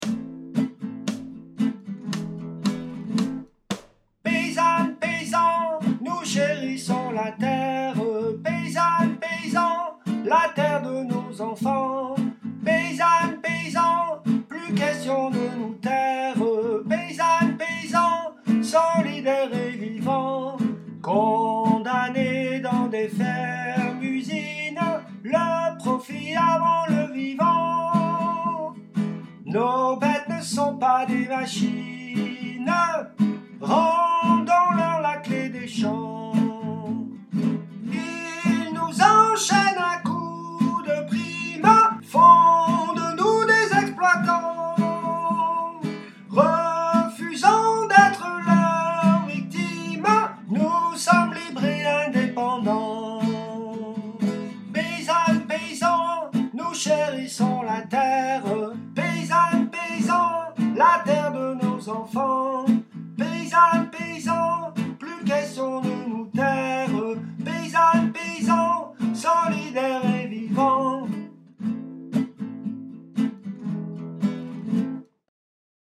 2. Soprani.mp3